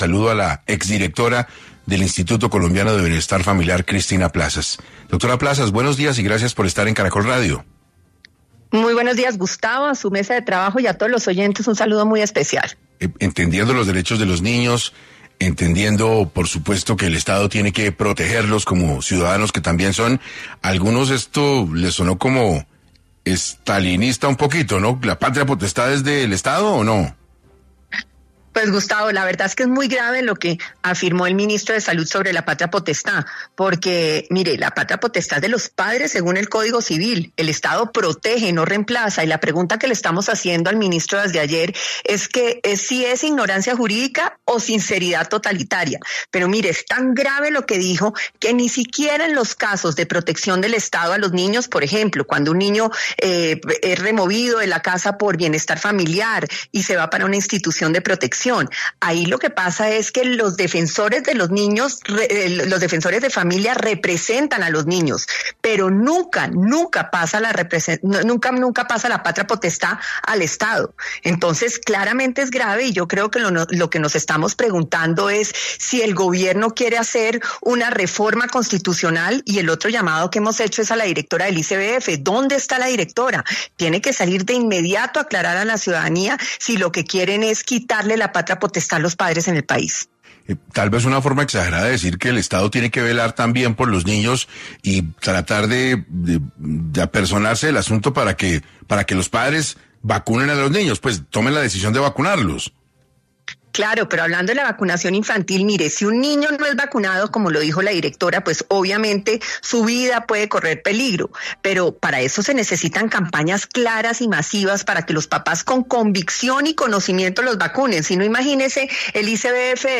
Para los micrófonos de 6AM, habló Cristina Plazas, exdirectora del ICBF, confrontó las declaraciones hachas por el ministro salud, Guillermo Alfonso Jaramillo y la actual directora del ICBF.